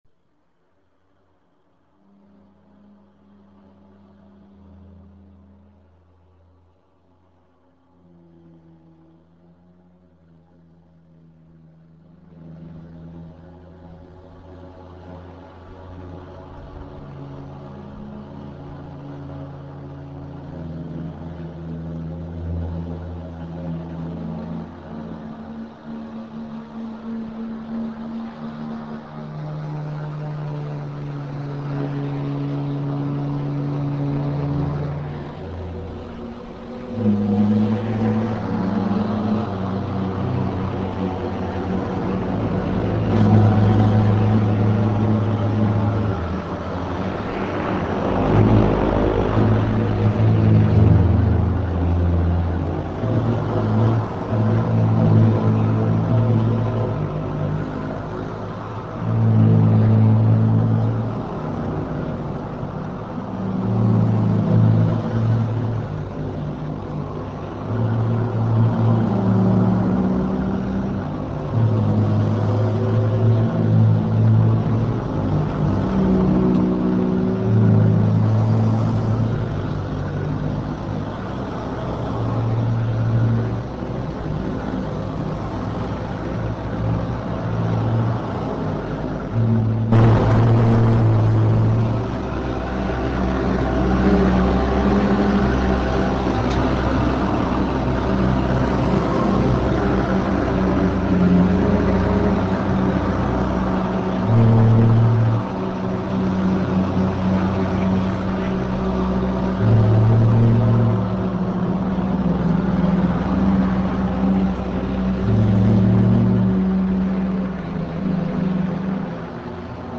Ozone live session